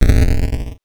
explosion_5.wav